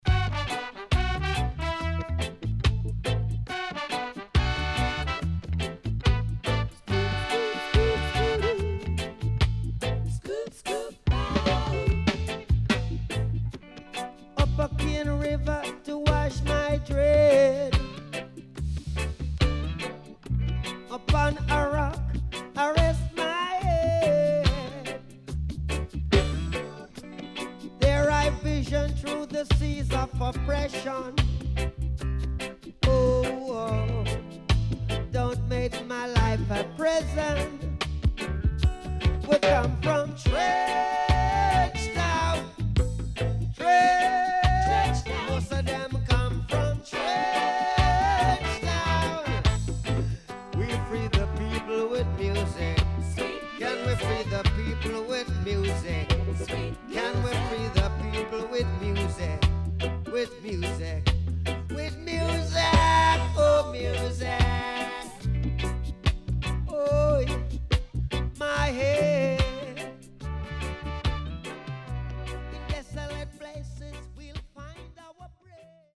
SIDE B:少しチリノイズ入りますが良好です。